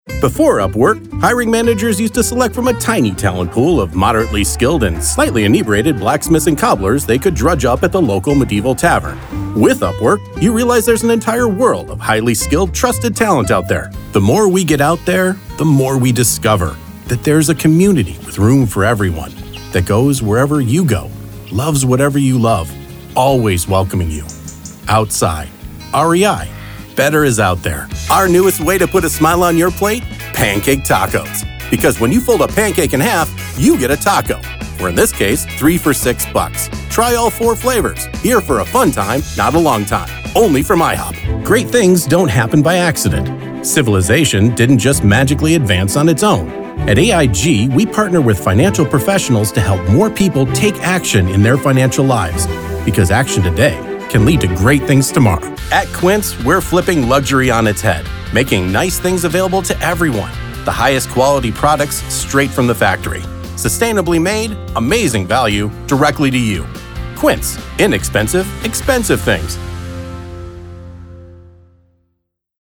Commercial
CAD Equitek E100
Fully Treated Booth (-60dB noise floor)
Male 40’s-60’s
Clear
Engaging
Friendly